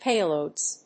/ˈpeˌlodz(米国英語), ˈpeɪˌləʊdz(英国英語)/